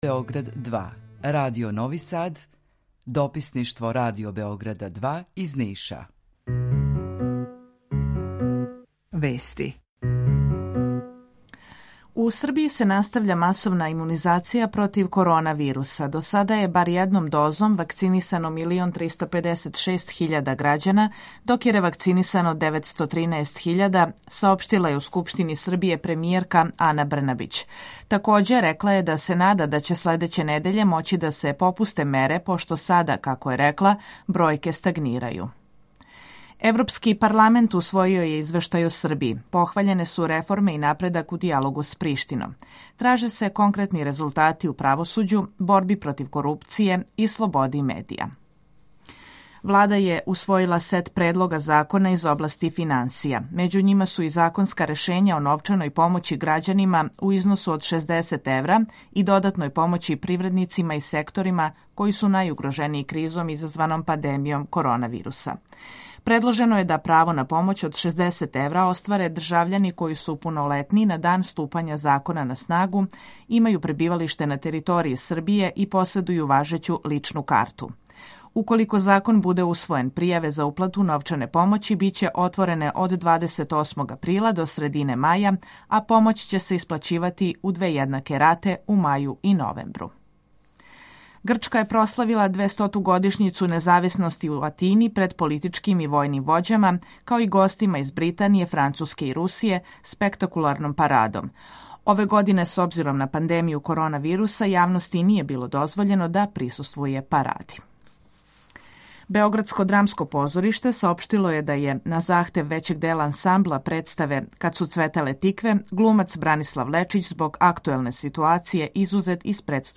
Укључење Бањалуке
Јутарњи програм из три студија
У два сата, ту је и добра музика, другачија у односу на остале радио-станице.